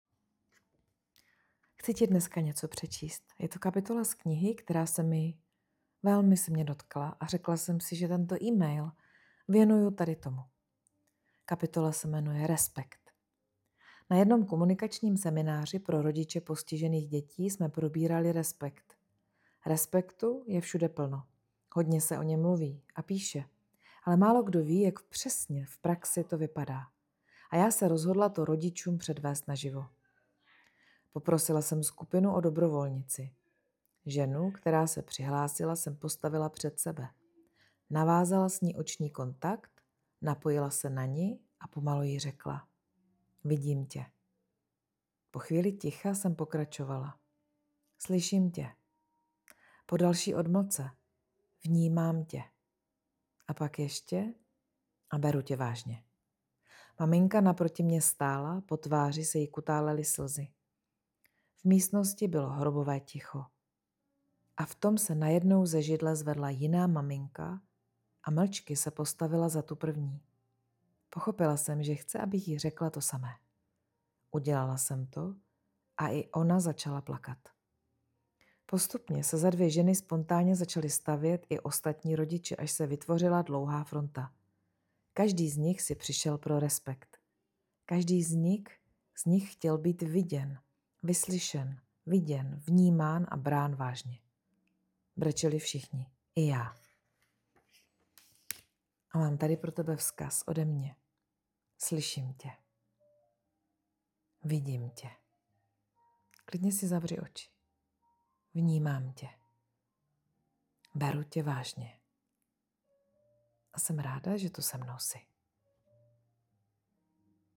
audio vzkaz.